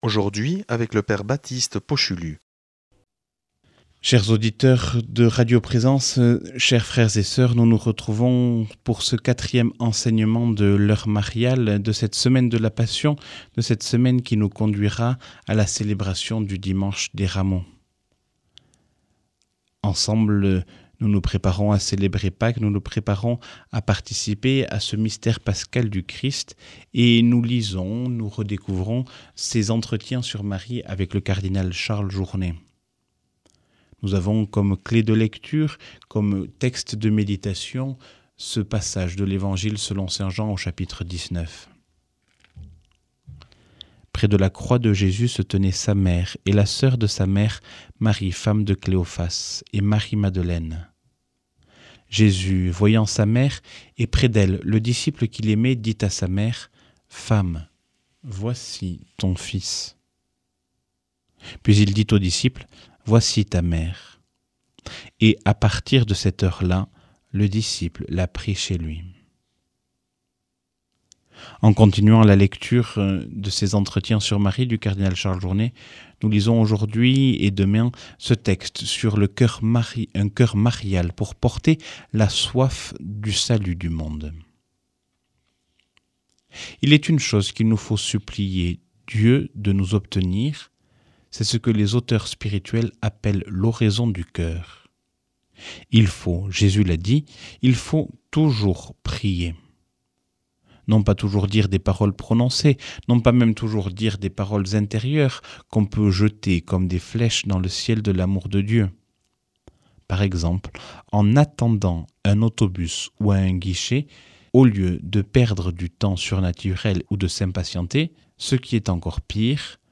Enseignement Marial du 10 avr.